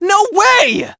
hotshot_hurt_07.wav